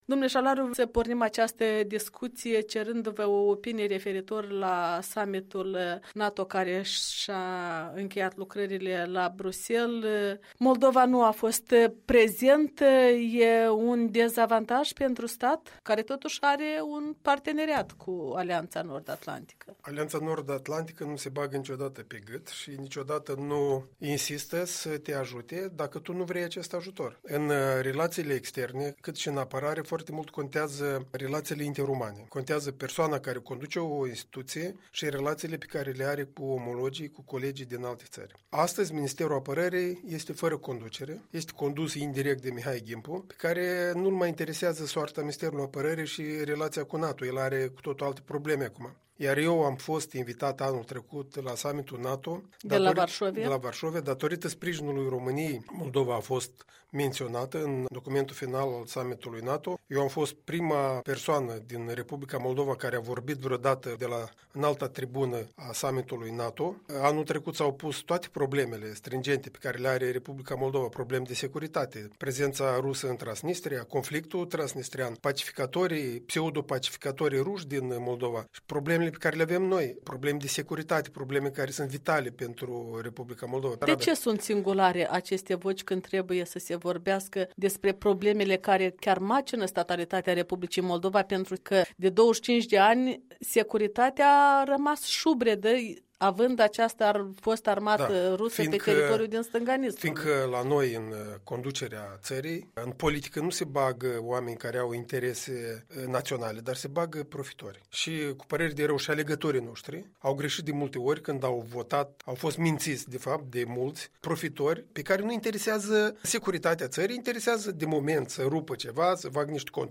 Interviu cu fostul ministru moldovean al apărării.